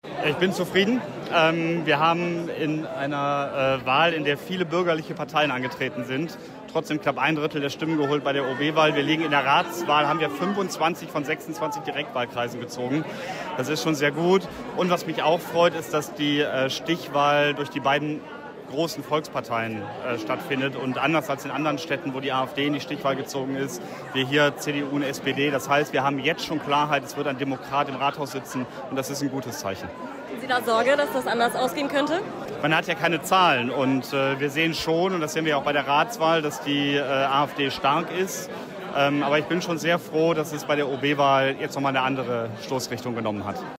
die sich bei der offiziellen Wahlparty in der Gläsernen Werkstatt am RSG-Mikro zu den Ergebnissen geäußert haben.